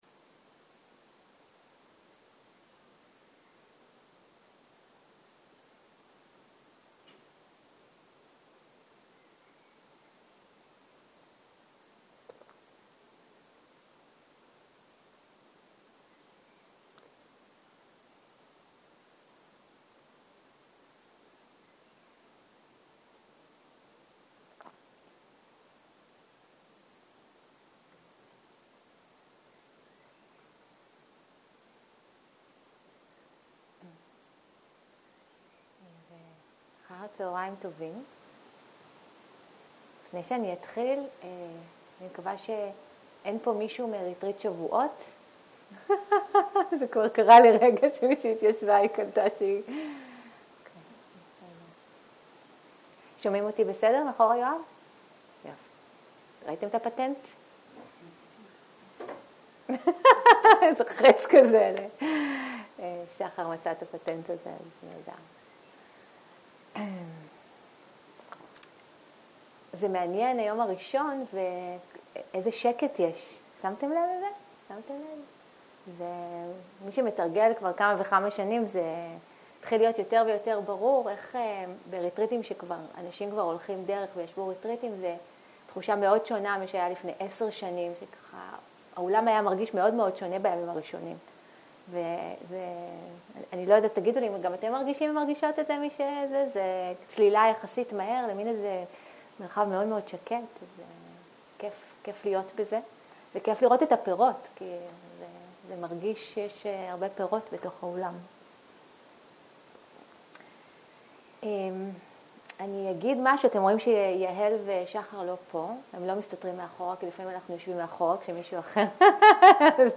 Dharma type: Dharma Talks